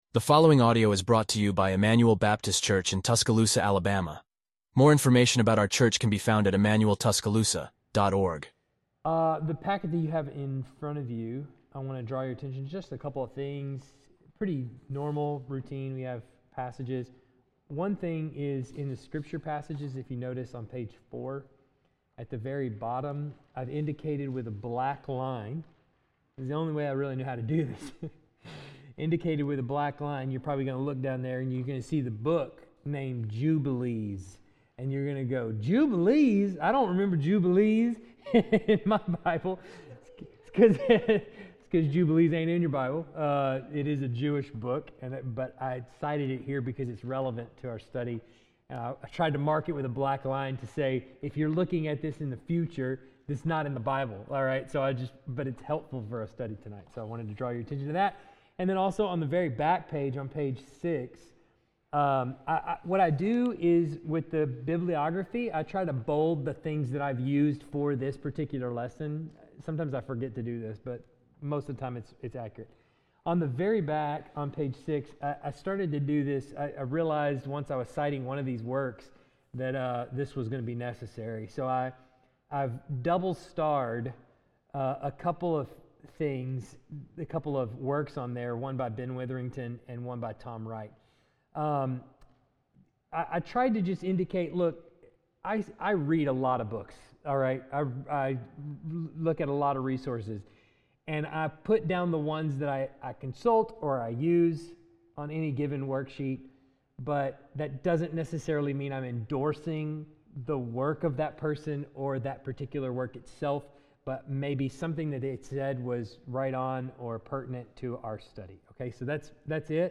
Wednesday evening Bible Study